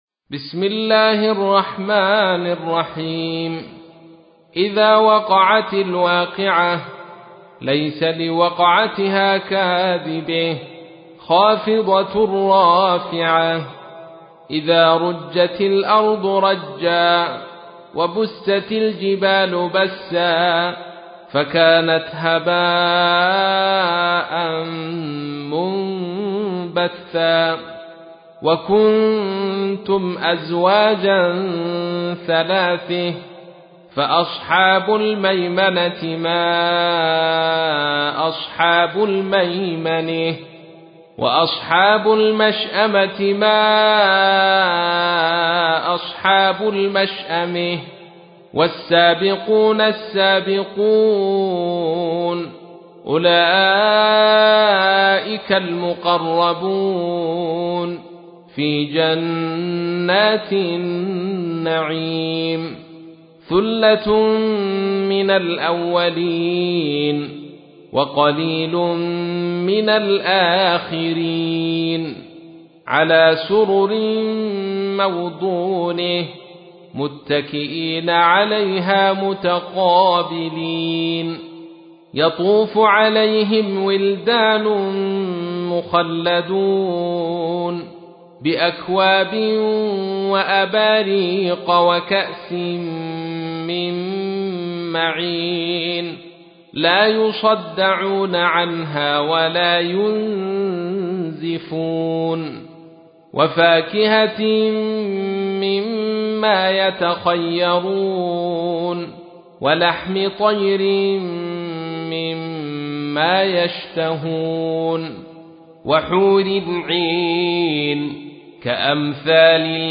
سورة الواقعة / القارئ